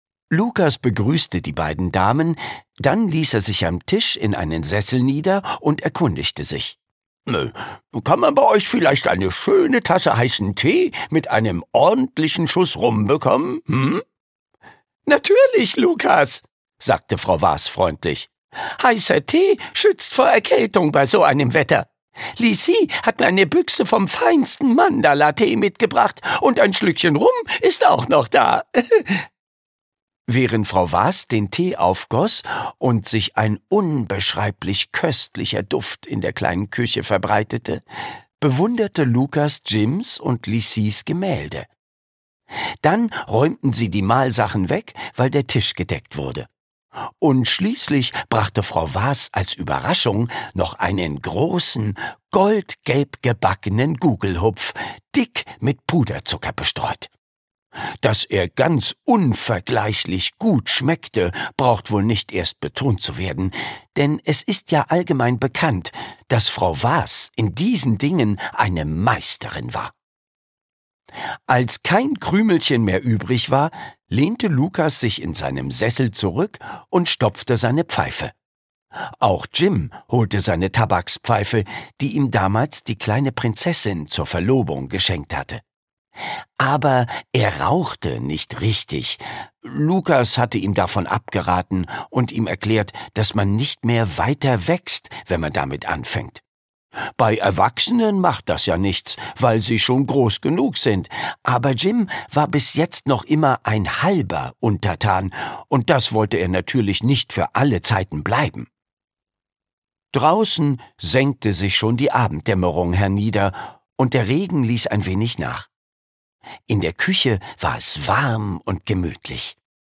Jim Knopf: Jim Knopf und die Wilde 13 - Die Komplettlesung - Michael Ende - Hörbuch